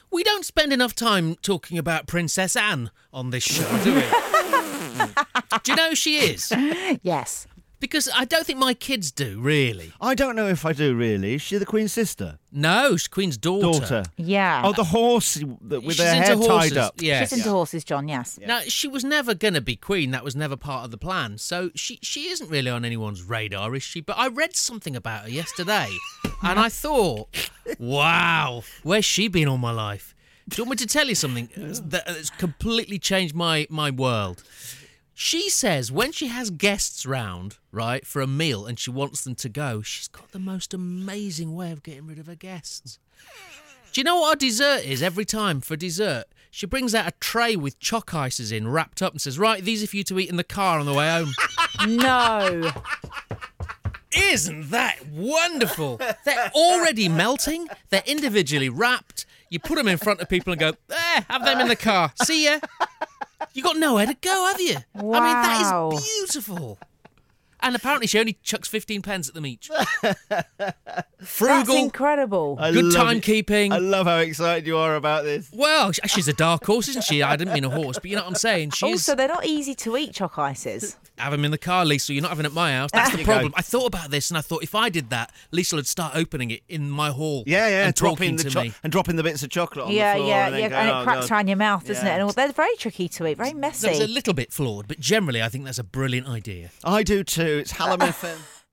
Radio Presenter
Conversational storyteller on breakfast radio